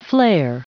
Prononciation du mot flair en anglais (fichier audio)
Prononciation du mot : flair